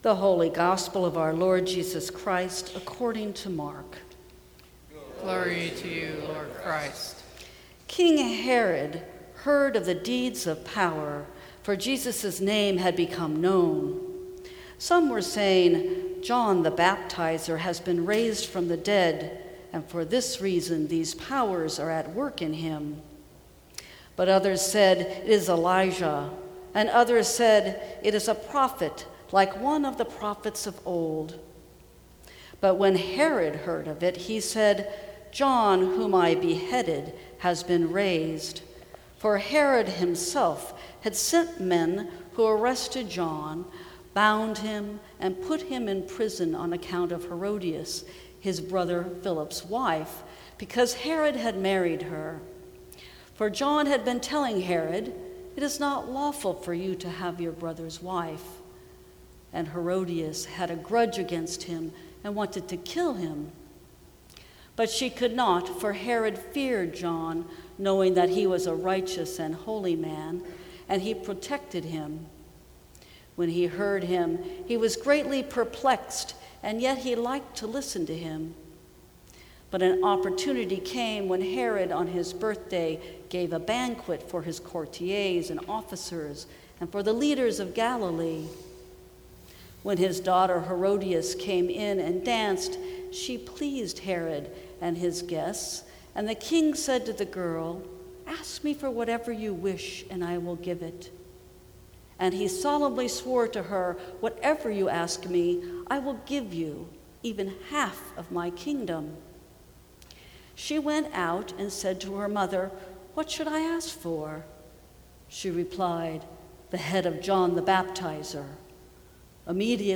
Sermons from St. Cross Episcopal Church Our Prayer of Power Jul 19 2018 | 00:14:13 1x 00:00 / 00:14:13 Subscribe Share Apple Podcasts Spotify Overcast RSS Feed Share Link Embed